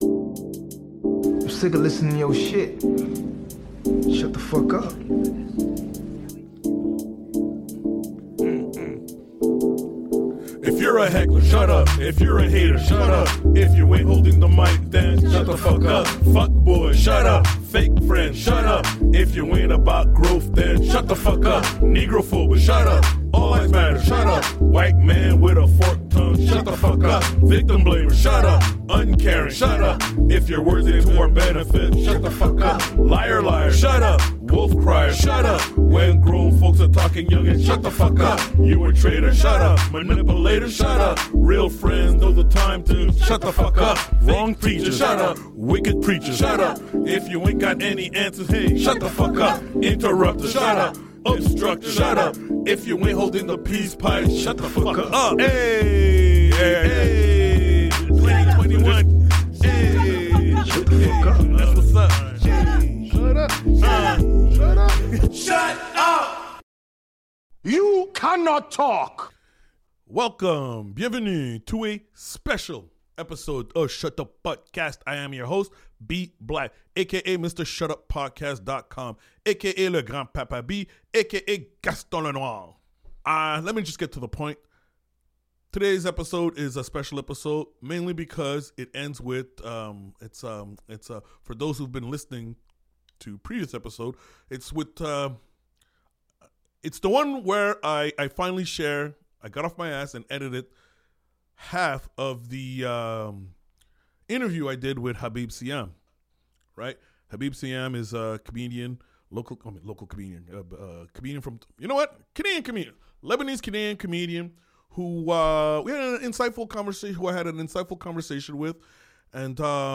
This is part 1 of the episode in which I interviewed comedian